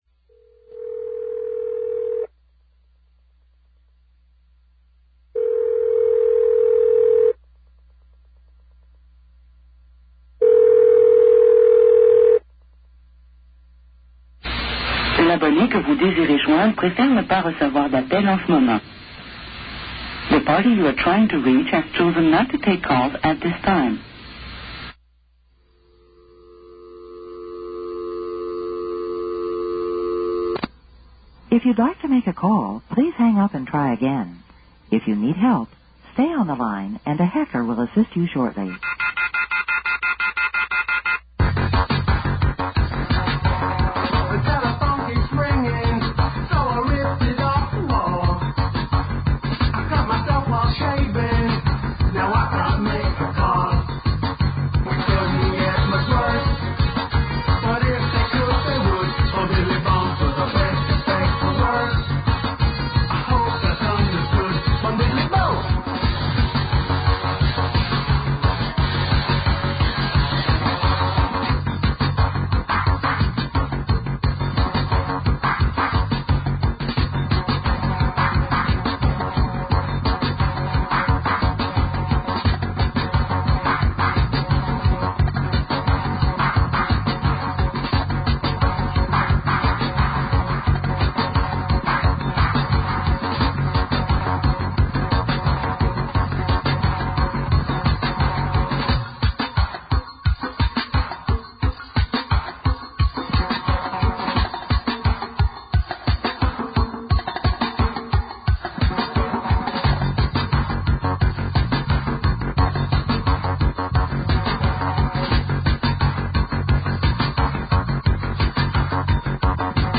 There are no levels for anyone connecting via Skype